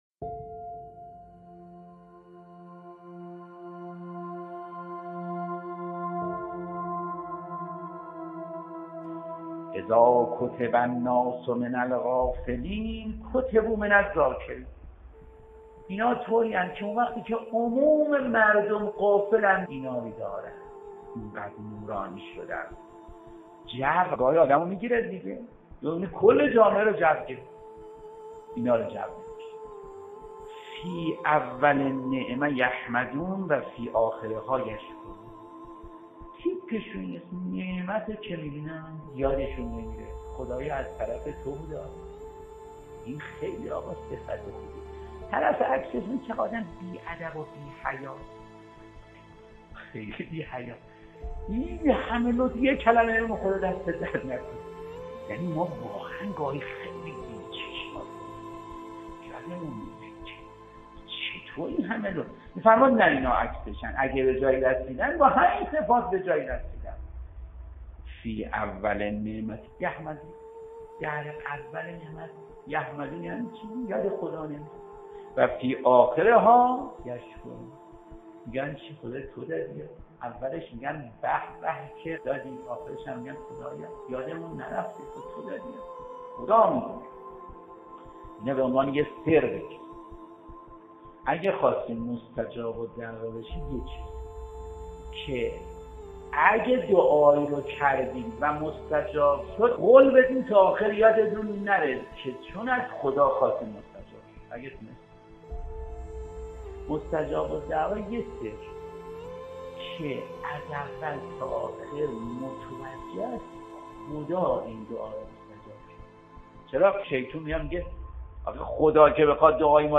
سخنرانی صوتی مذهبی